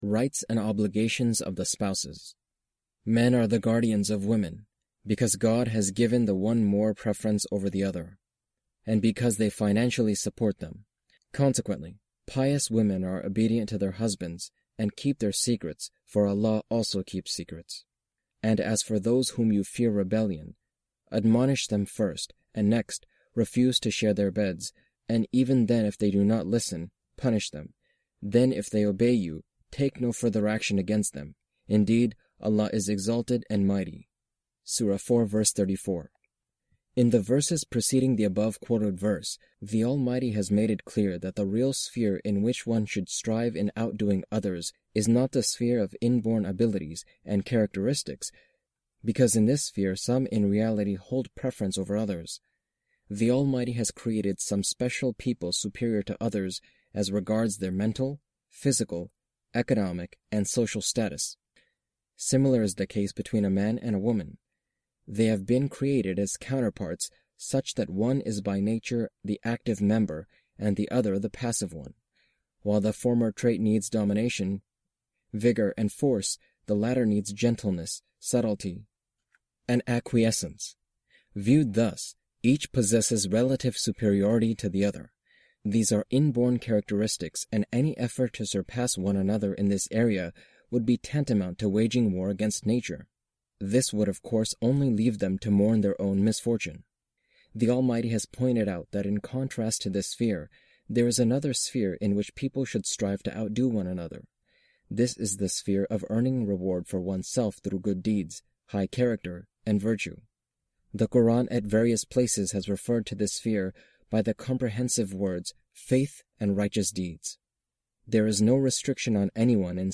Audio book of English translation of Javed Ahmad Ghamidi's book "Mizan".